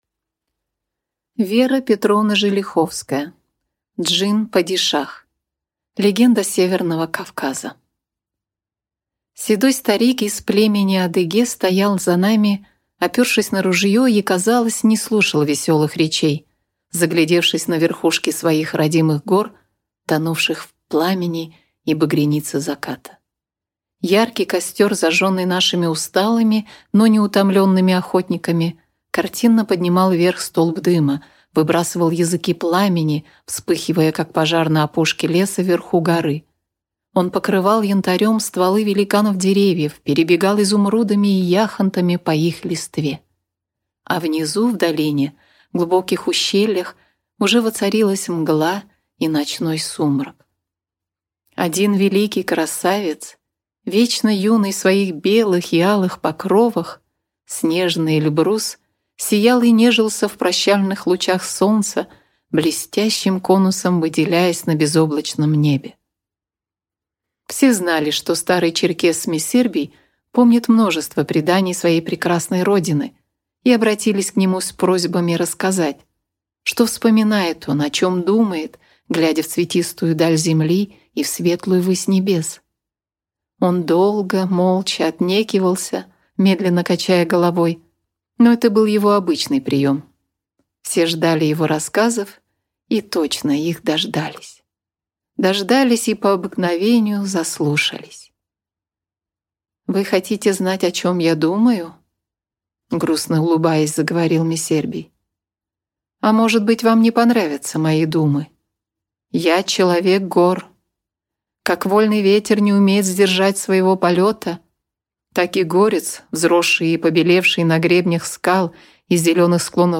Aудиокнига Джин-Падишах